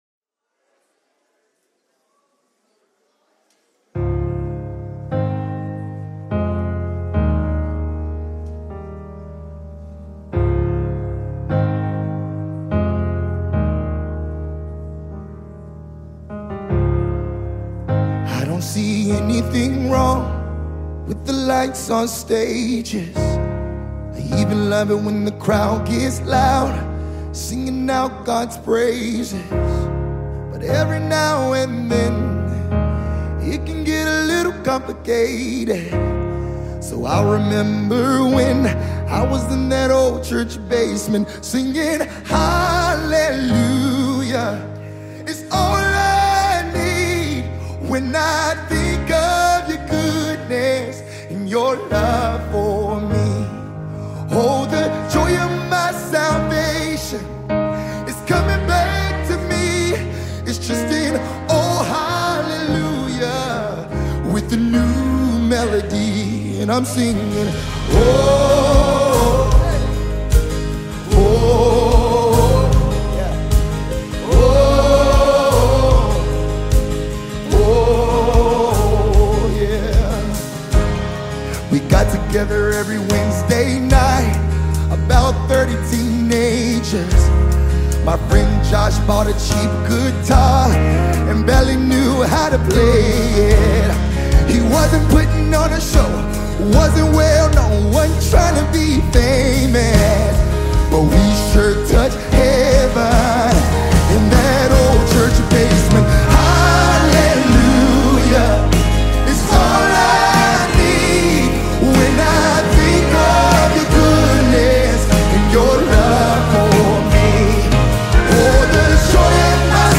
worship groups
collaborative live album